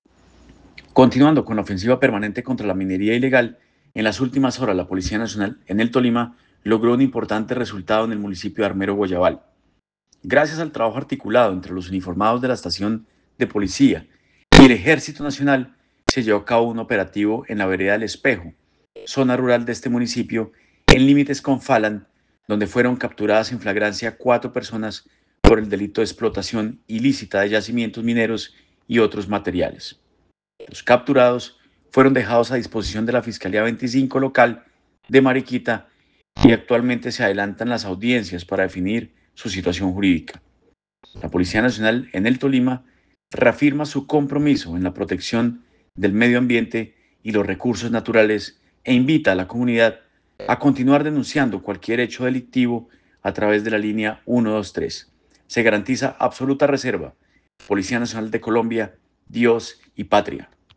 Audio Coronel John Anderson Vargas Izao Comandante Departamento de Policía Tolima.